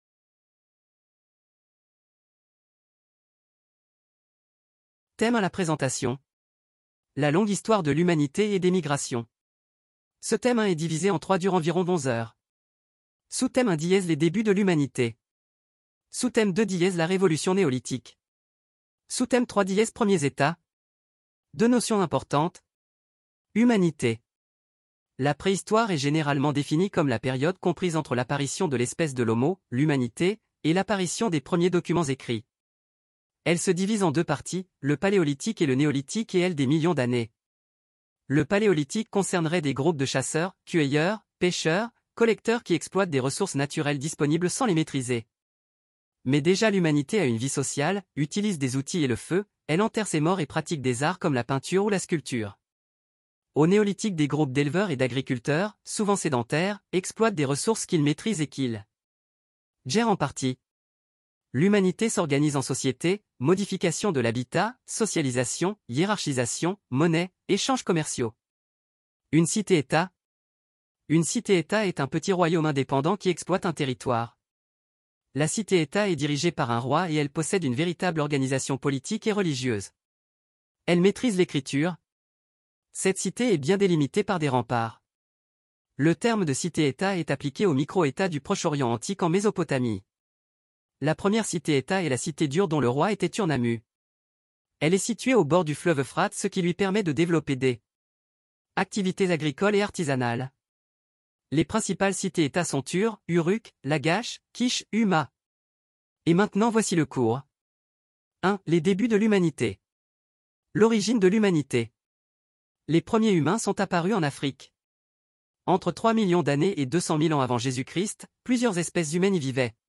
Cours audio 6e histoire